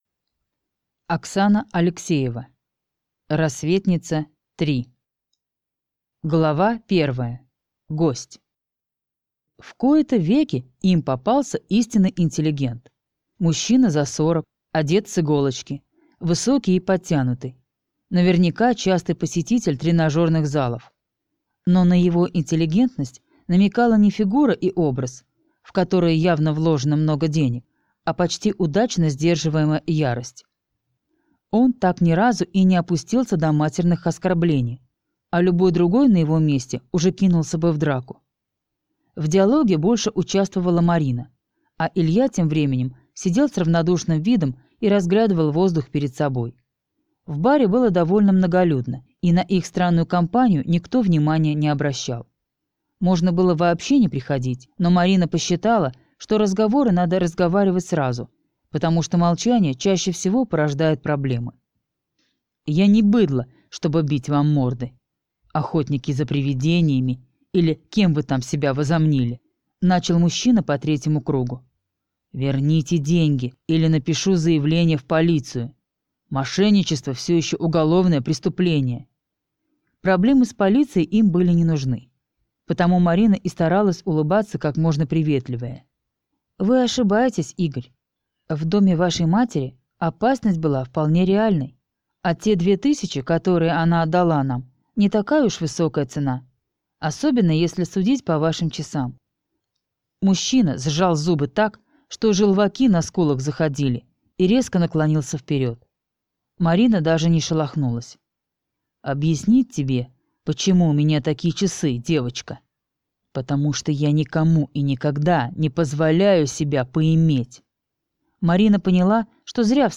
Аудиокнига Рассветница-3: Реалити-шоу | Библиотека аудиокниг